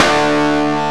Index of /90_sSampleCDs/Roland L-CD701/GTR_Distorted 1/GTR_Power Chords
GTR DIST G2.wav